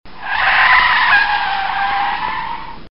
На этой странице собраны реалистичные звуки тормозов автомобилей: от резкого визга до плавного скрипа.
звук визга тормозов для сценки